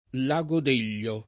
vai all'elenco alfabetico delle voci ingrandisci il carattere 100% rimpicciolisci il carattere stampa invia tramite posta elettronica codividi su Facebook Lago Delio [ l #g o d $ l L o ] o Lago d’Elio [id.] top. m. (Lomb.) — anche lago Deglio [ l #g o d % l’l’o ]